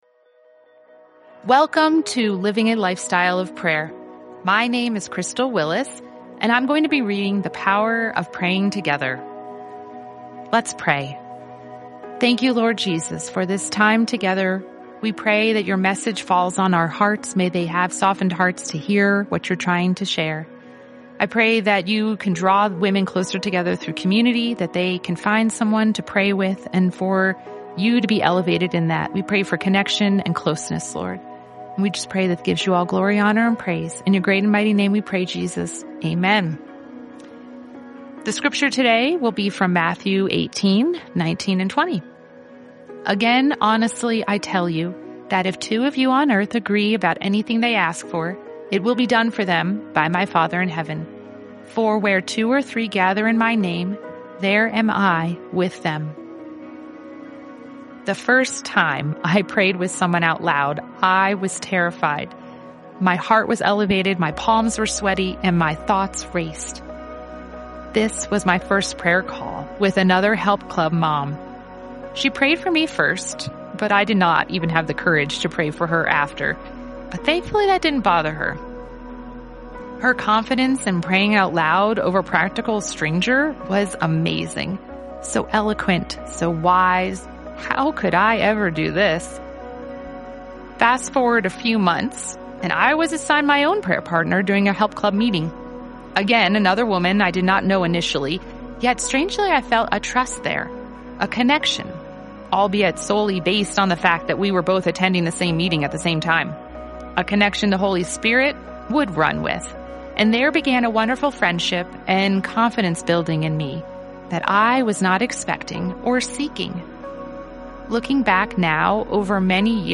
Living a Lifestyle of Prayer: 30-Day Audio Devotional for Moms